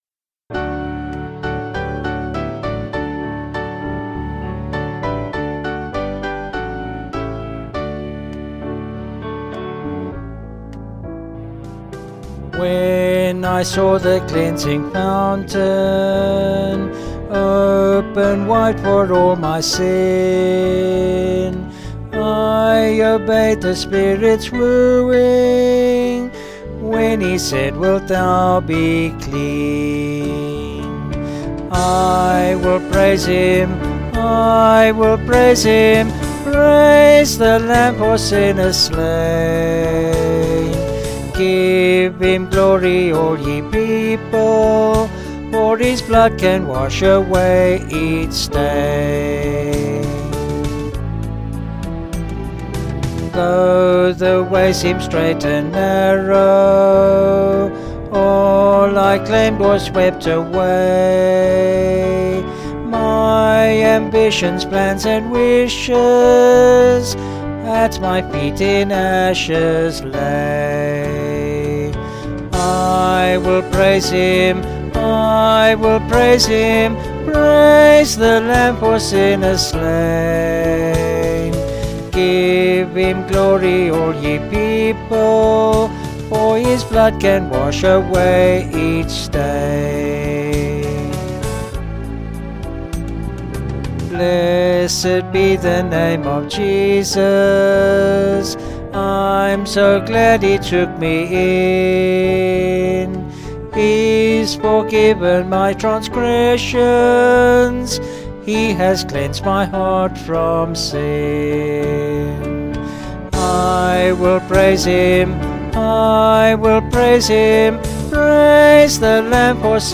Vocals and Band   264kb Sung Lyrics